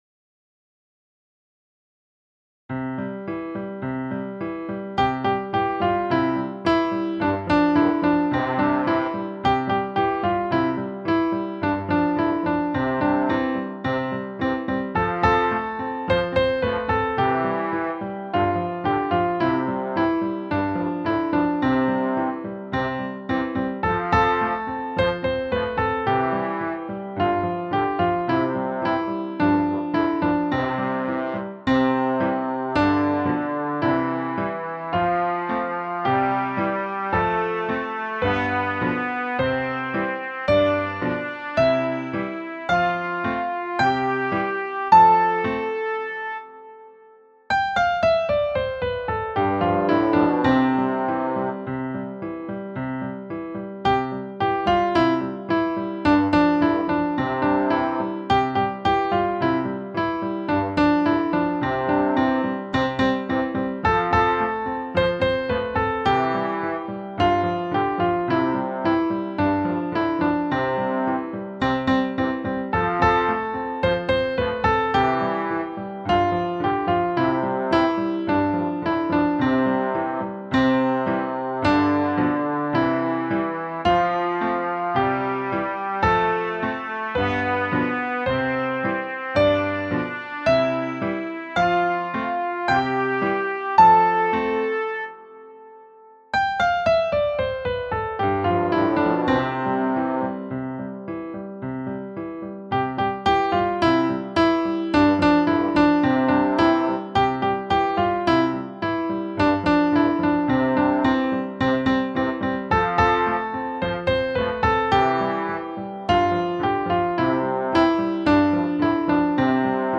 base musicale della canzoncina